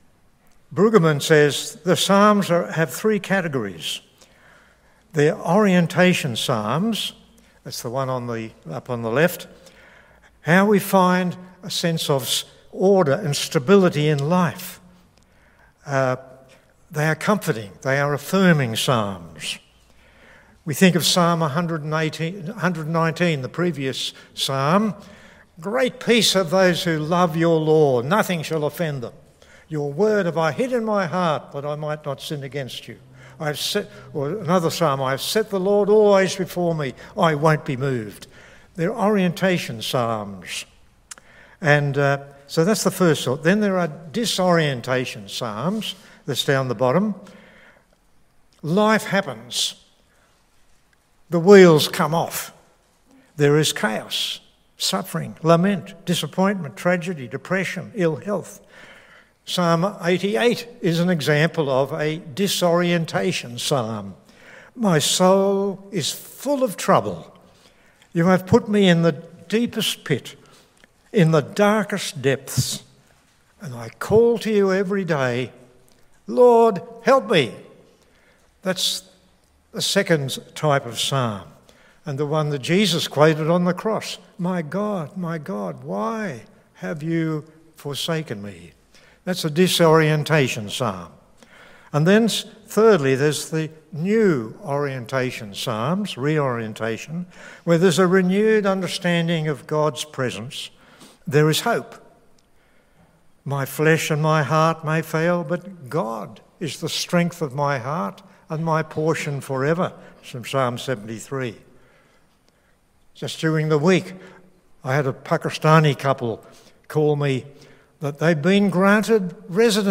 Sermon on Psalm 127